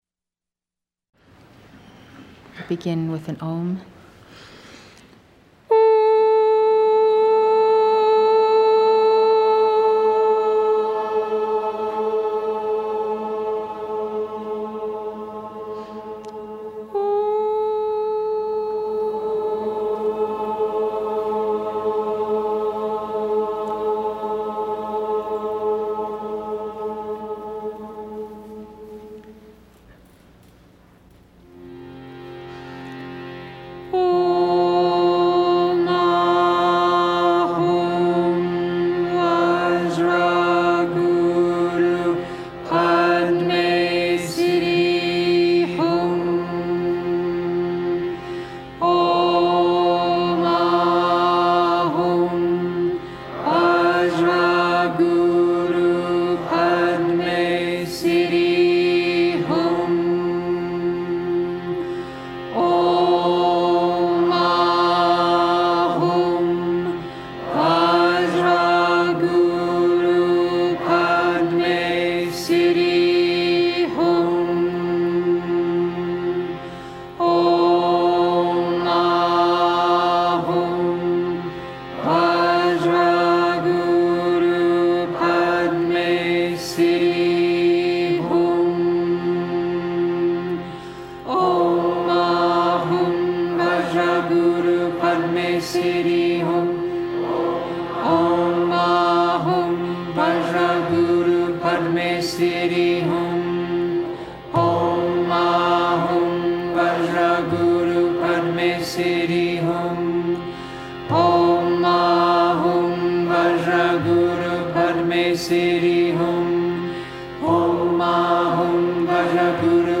Aprende a hacer el Mantra Dorado con la Mensajera de la Gran Hermandad Blanca